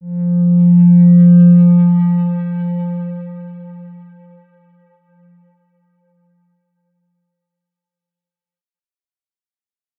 X_Windwistle-F2-pp.wav